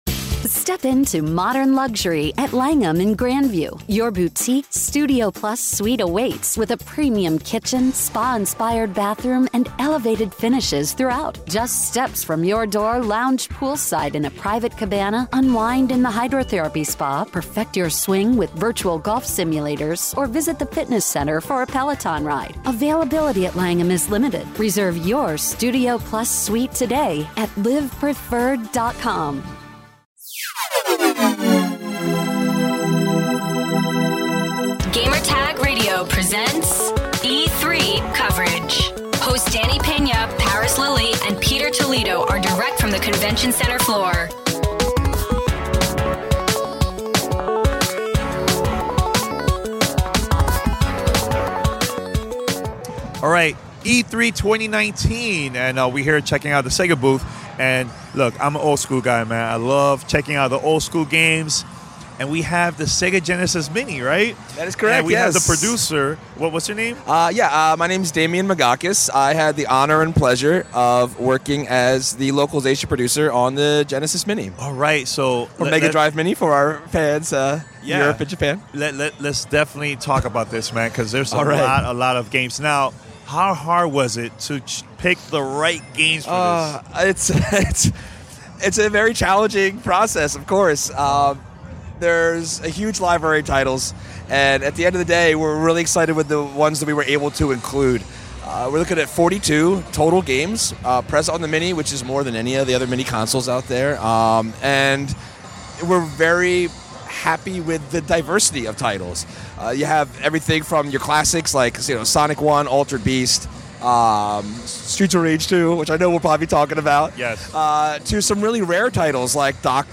E319: Sega Genesis Mini Interview